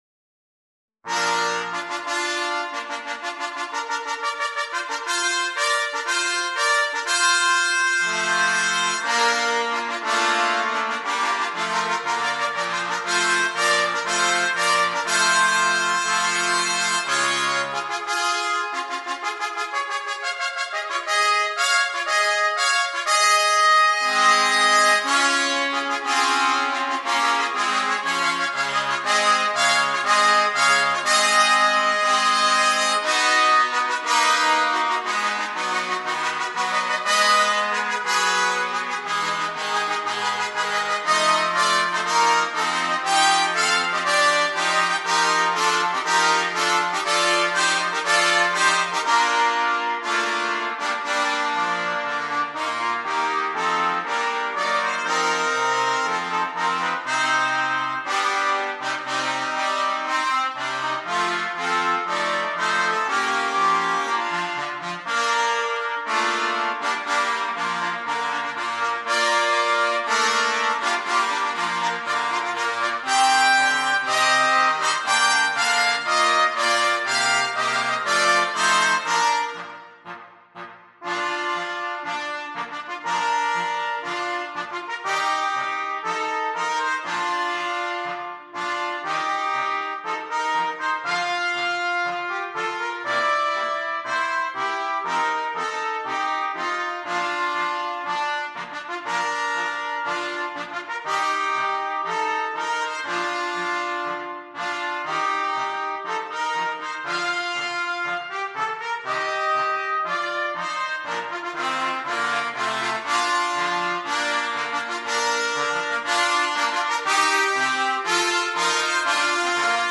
Per 2 trombe, trombone 1 (o corno in Fa) e trombone 2.